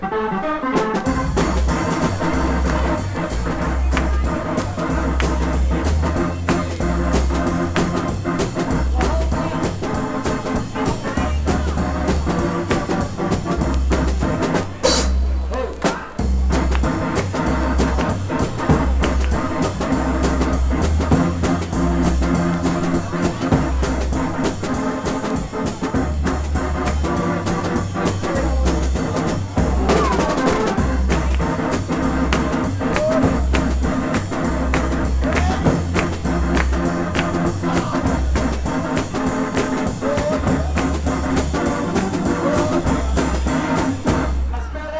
Rock, Rock'nRoll, Twist, Pop　USA　12inchレコード　33rpm　Stereo